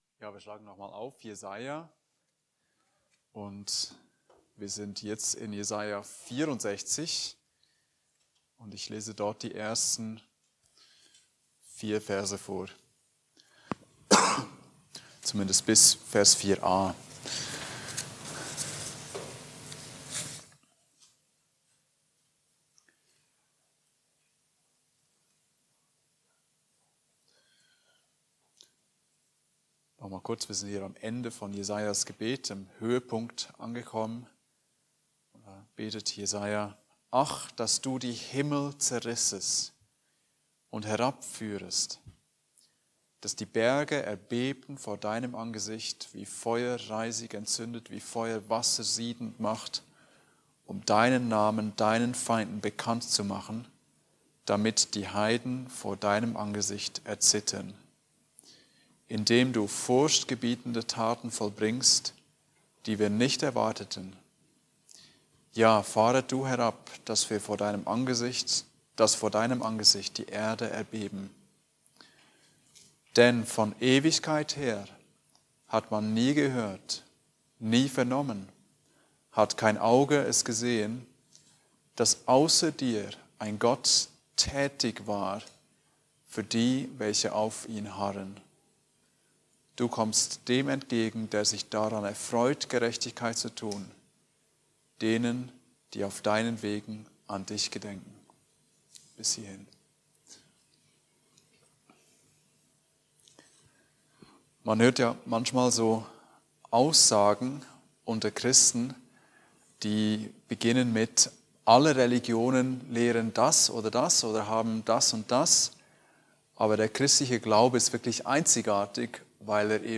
Kein anderer Gott (Andacht Gebetsstunde)